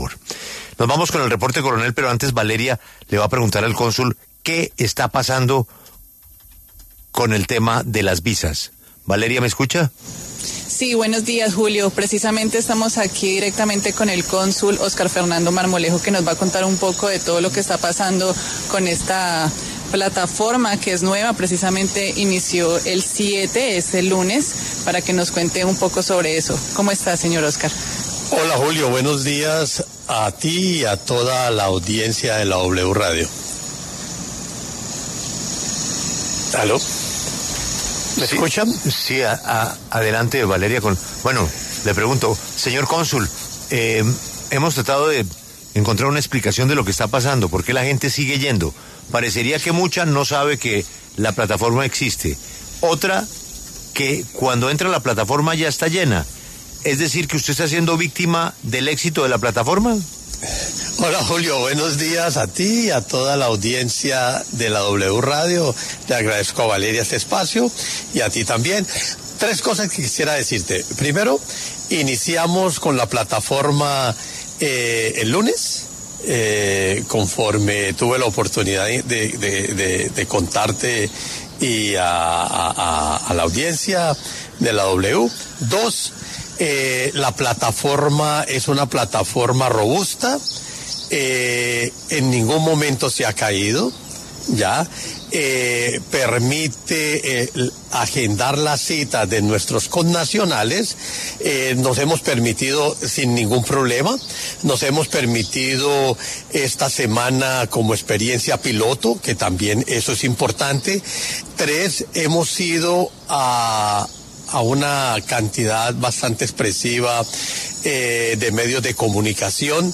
En diálogo con La W, el cónsul de Colombia en Miami, Óscar Marmolejo, respondió a las denuncias que han hecho varios ciudadanos colombianos en La W acerca de las dificultades para acceder a citas virtuales y atención presencial.